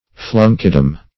Flunkydom \Flun"ky*dom\, n. The place or region of flunkies.